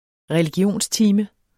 Udtale [ ʁεliˈgjoˀns- ]